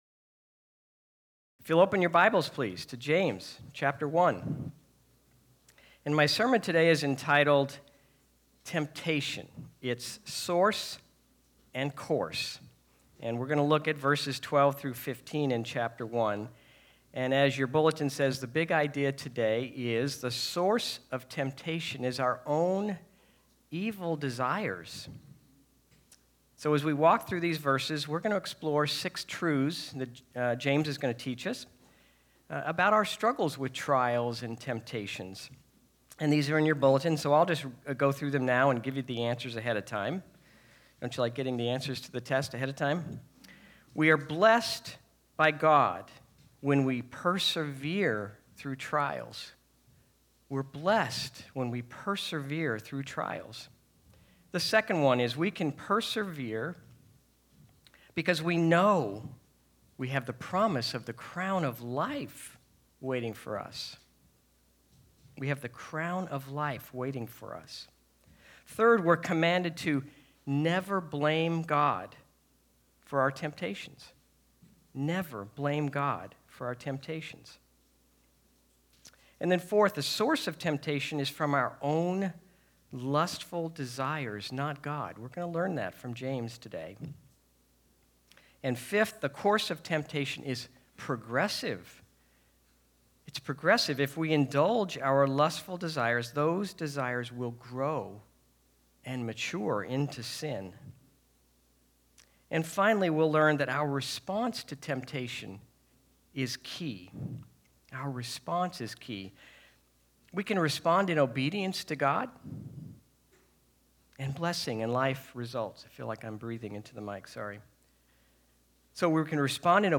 Service Type: Sunday Sermons